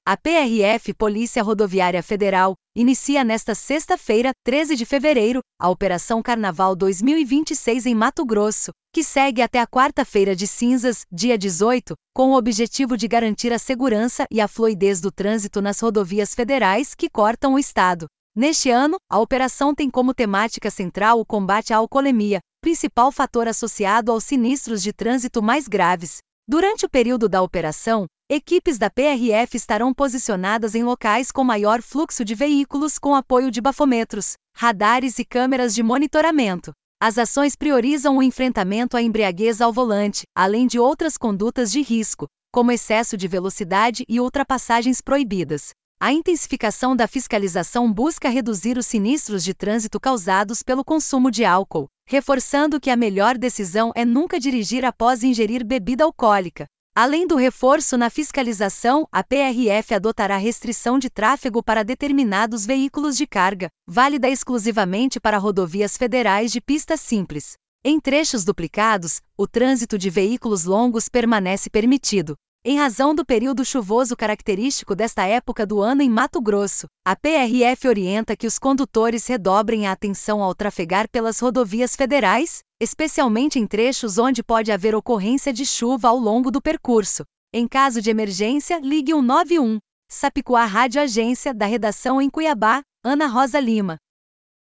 Boletins de MT 11 fev, 2026